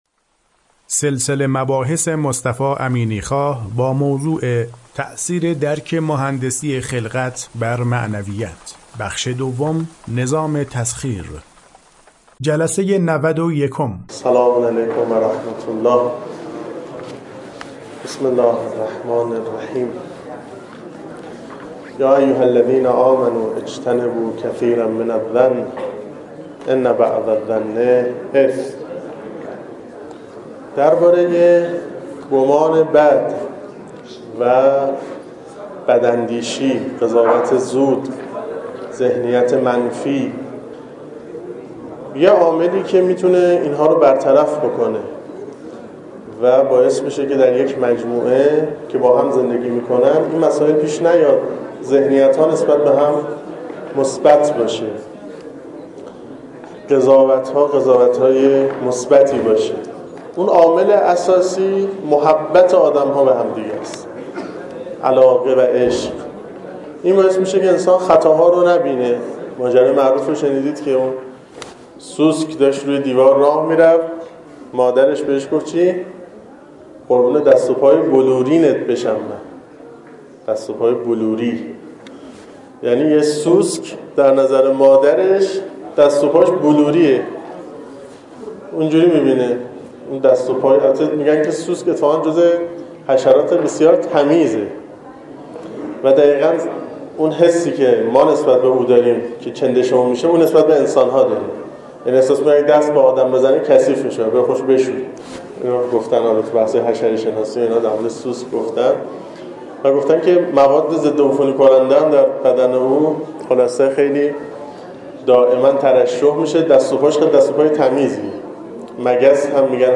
سلسله مباحث مهندسی خلقت که در دانشکده مهندسی دانشگاه فردوسی ارائه شده در چند بخش پیگیری می شود که شمای کلی آن بدین شرح است: